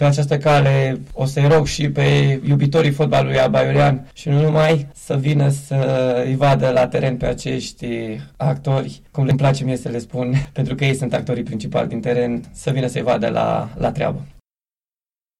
Prezent în studioul Unirea FM